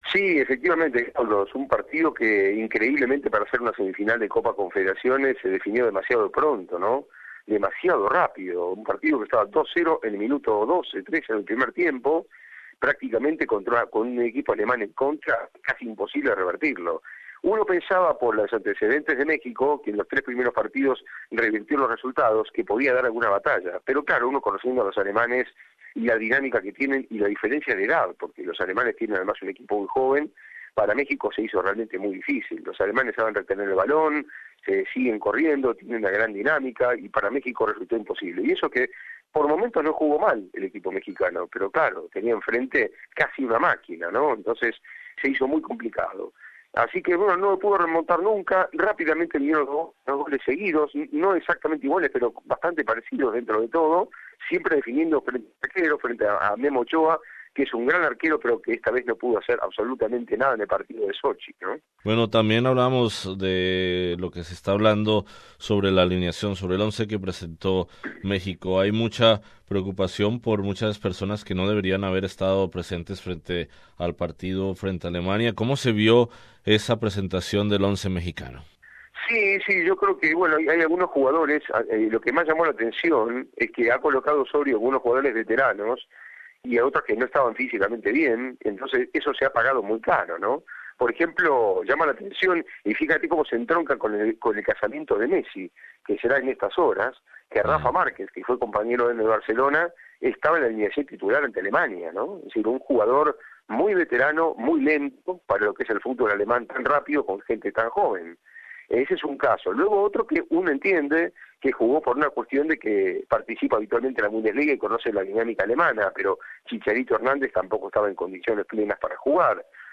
En análisis con nuestro corresponsal en Rusia